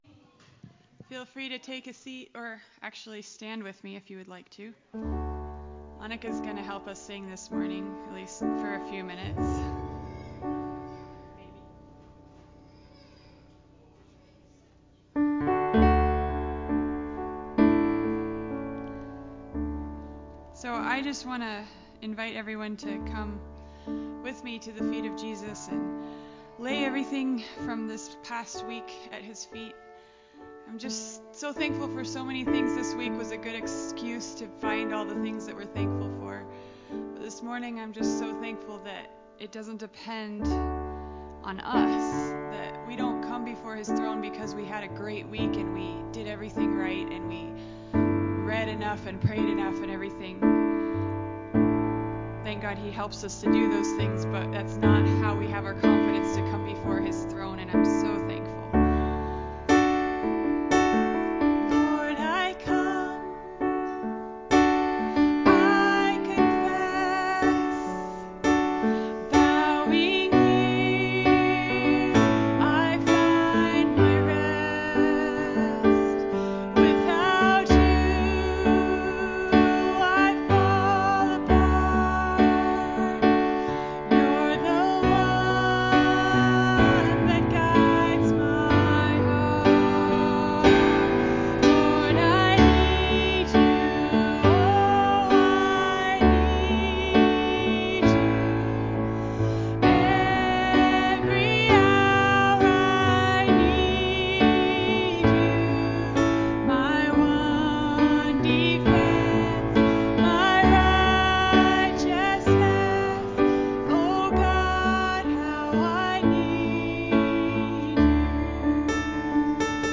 Live Broadcast-Dec 1 2024 – Redeemed Community Church